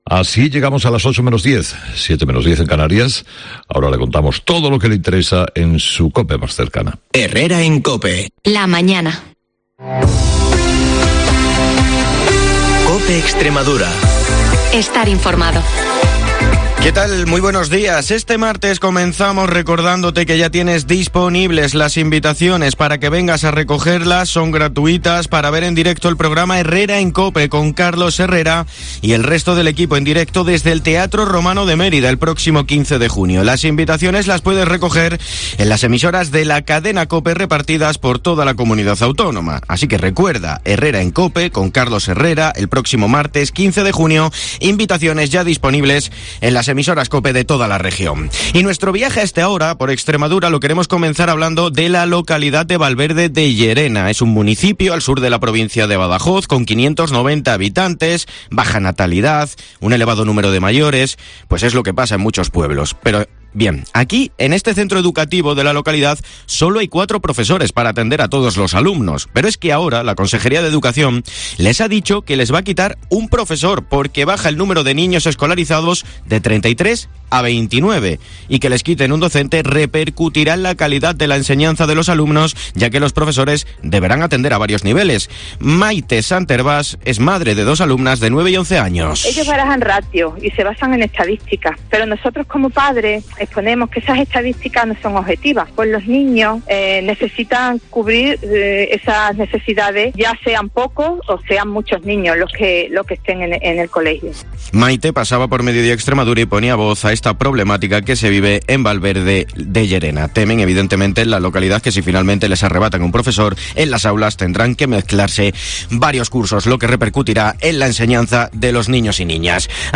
el informativo líder de la radio en la región